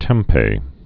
(tĕmpā)